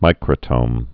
(mīkrə-tōm)